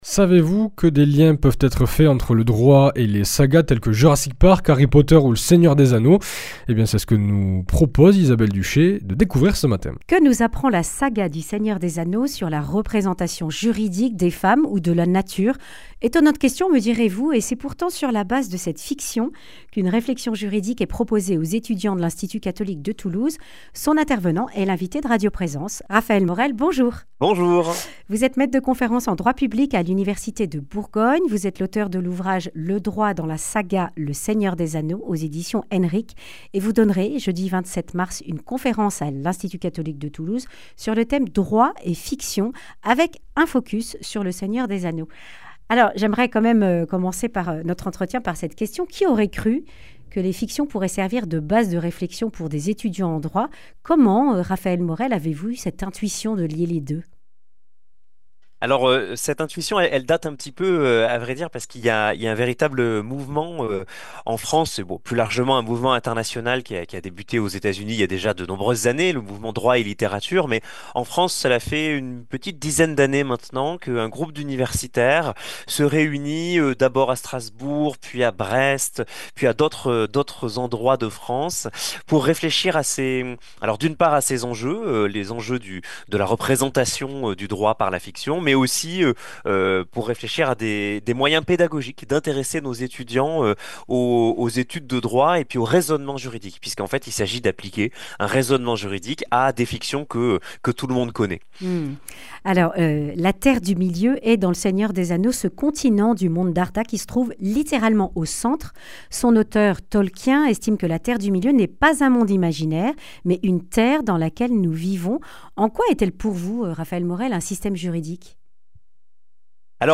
Accueil \ Emissions \ Information \ Régionale \ Le grand entretien \ Quels liens entre Le Seigneur des Anneaux et le droit ?